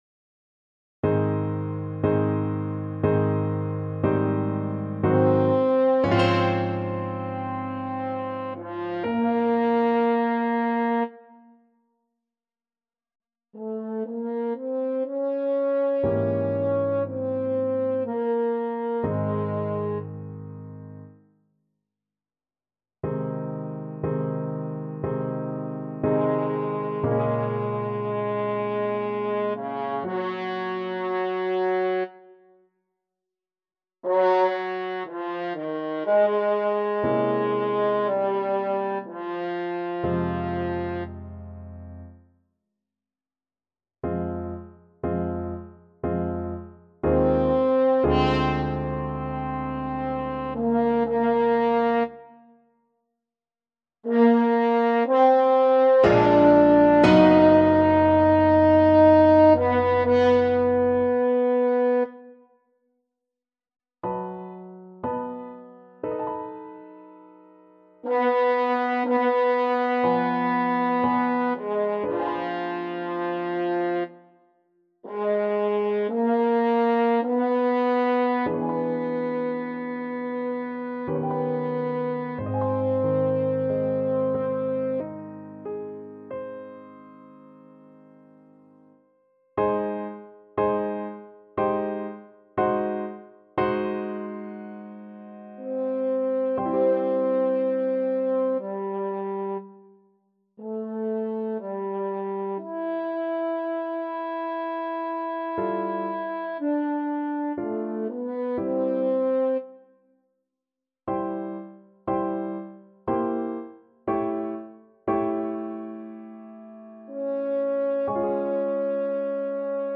Play (or use space bar on your keyboard) Pause Music Playalong - Piano Accompaniment Playalong Band Accompaniment not yet available reset tempo print settings full screen
French Horn
Ab major (Sounding Pitch) Eb major (French Horn in F) (View more Ab major Music for French Horn )
3/4 (View more 3/4 Music)
~ = 60 Langsam, leidenschaftlich
Classical (View more Classical French Horn Music)